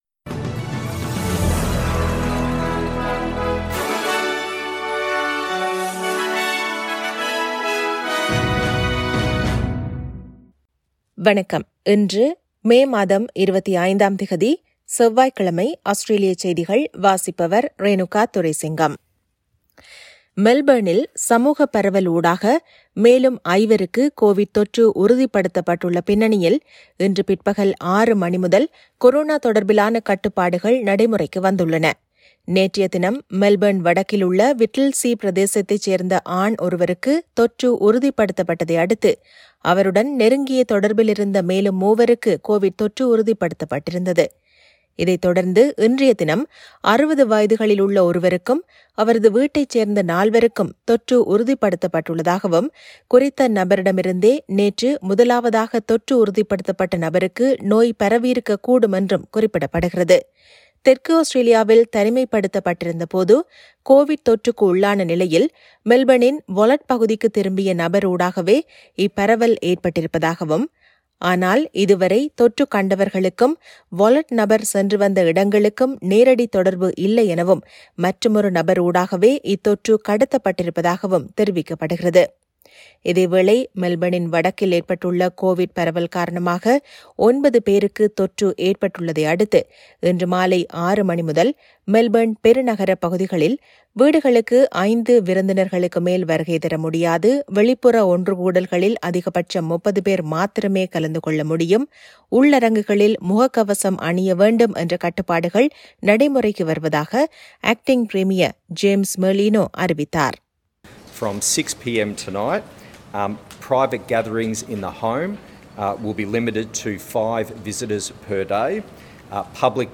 Australian news bulletin for Tuesday 25 May 2021.